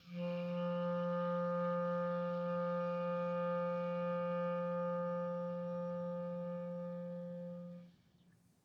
Clarinet
DCClar_susLong_F2_v1_rr1_sum.wav